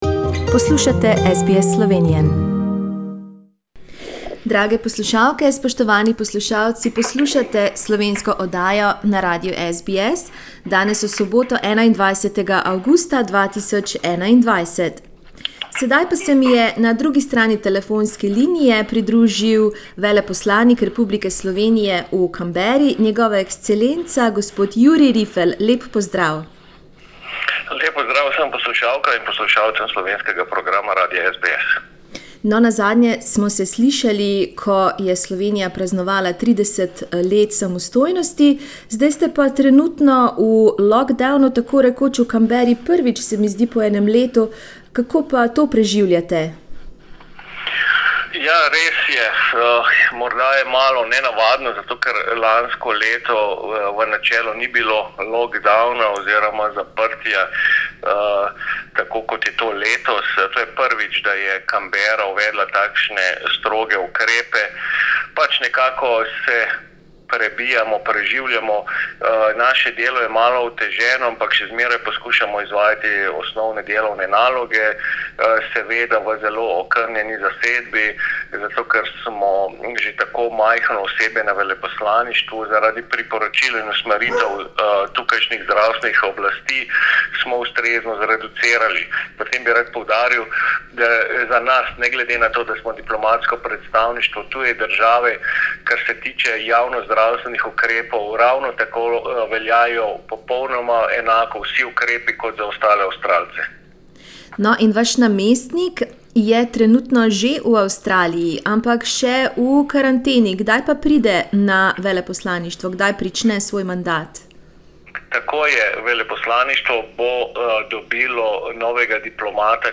Z veleposlanikom RS v Canberri Jurijem Rifljem smo se pogovarjali o trenutnem zaprtju avstralske prestolnice, o predsedovanju Slovenije Evropski uniji ter o novem e-obrazcu, ki je po novem obvezen za vstop v Slovenijo.